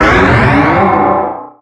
Cri de Méga-Dracaufeu X dans Pokémon HOME.
Cri_0006_Méga_X_HOME.ogg